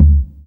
44_31_tom.wav